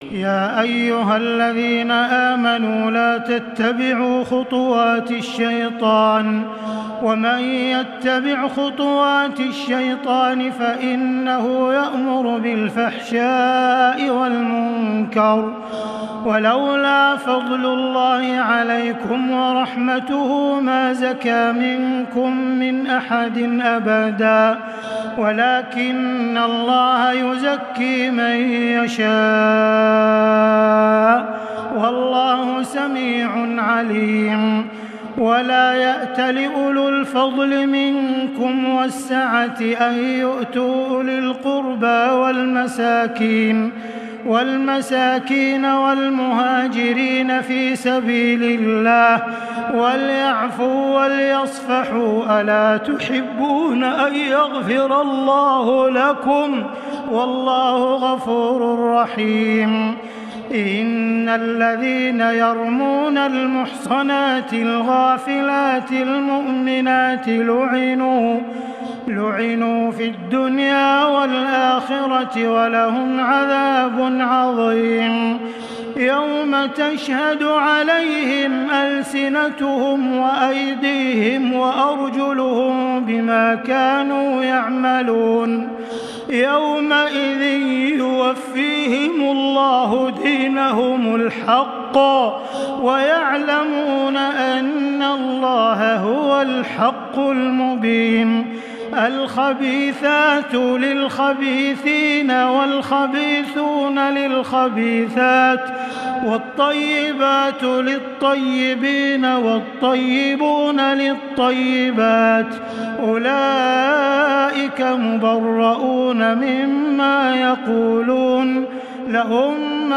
تراويح الليلة السابعة عشر رمضان 1440هـ من سورتي النور (21-64) و الفرقان (1-20) Taraweeh 17 st night Ramadan 1440H from Surah An-Noor and Al-Furqaan > تراويح الحرم المكي عام 1440 🕋 > التراويح - تلاوات الحرمين